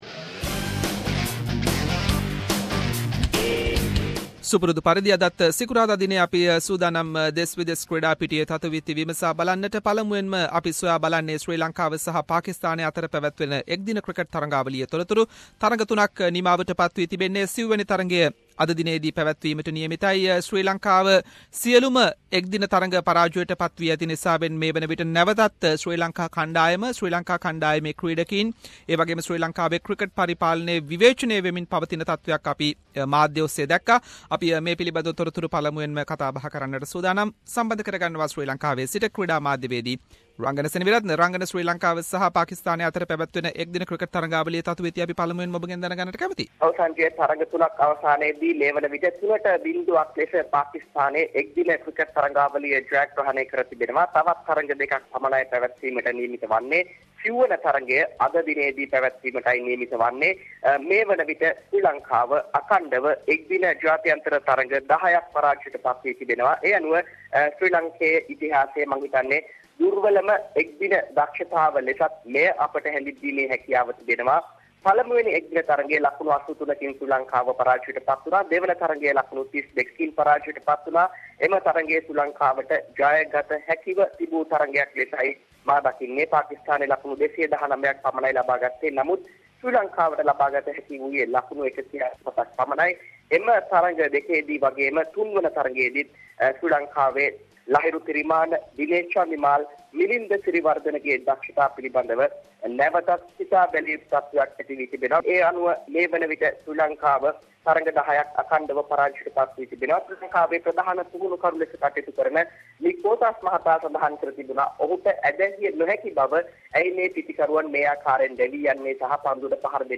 Weekly Sports wrap – Sri Lanka cricket confirms to play T20 match in Lahore